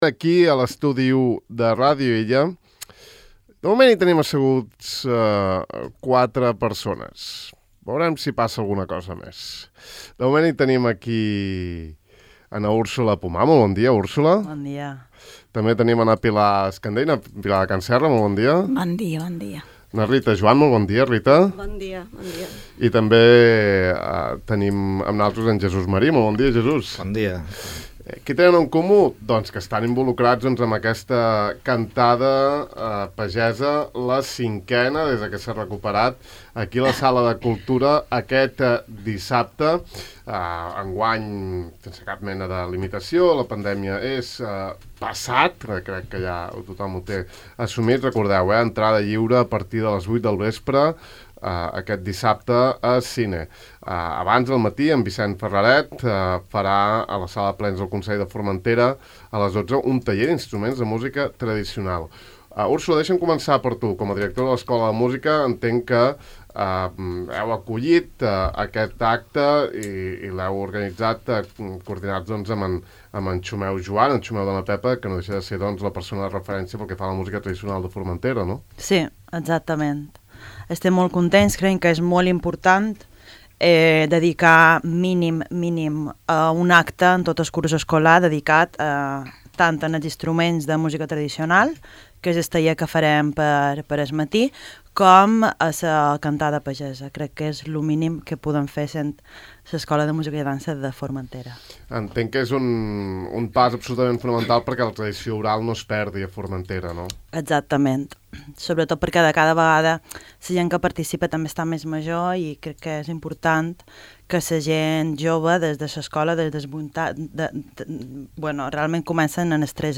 A més a més, tres persones han irromput a l’estudi 1 de Ràdio Illa, autoconvidats, i exigint el micròfon per cantar…